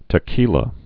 (tə-kēlə)